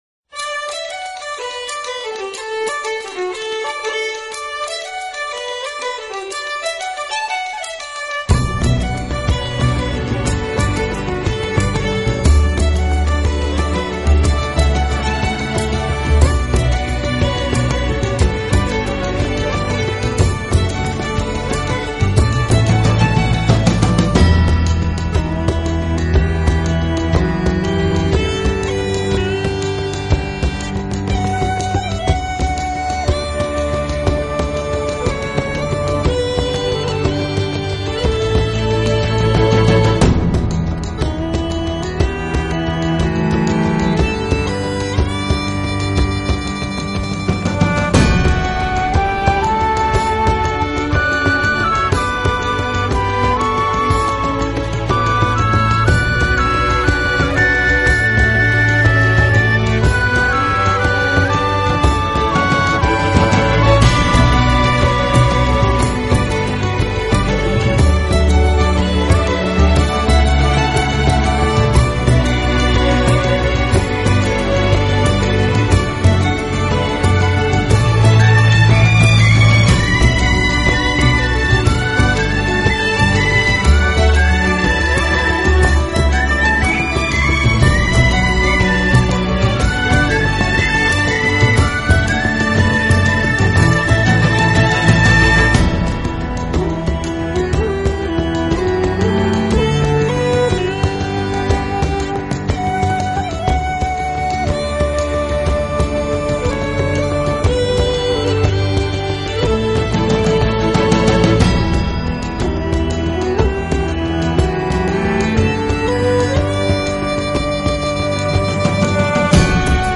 苏格兰如泣如诉的风笛之精选